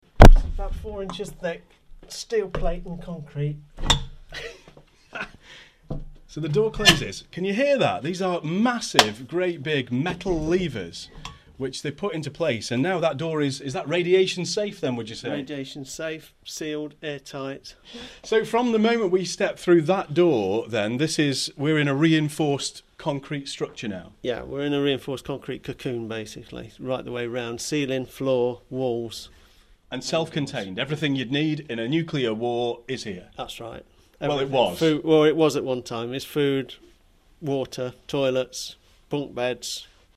A snippet of tomorrow's journey underground into the nuclear bunker in West Bridgford. More at 8.15am on BBC Radio Nottingham